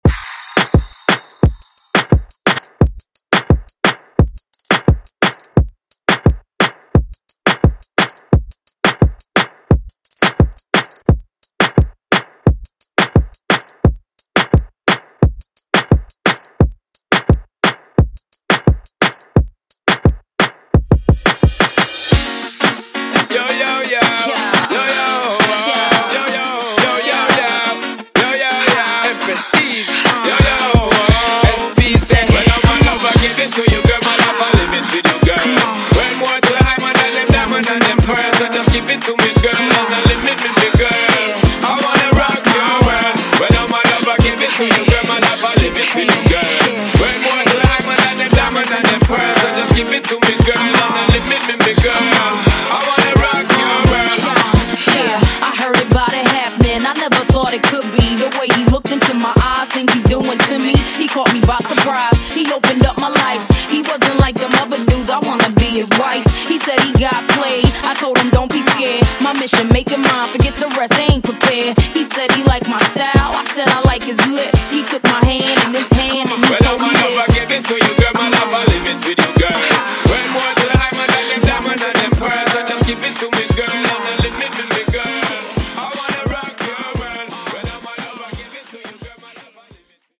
OldSkool Moombah)Date Added